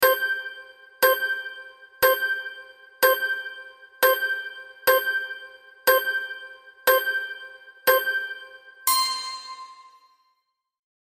timer_countdown Sound Button: Unblocked Meme Soundboard